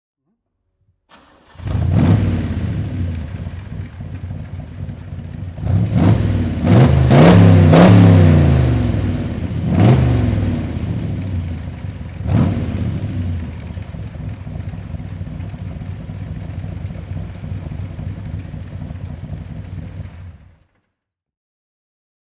Chevrolet Corvette Sting Ray 427/425 Convertible (1966) - Starten und Leerlauf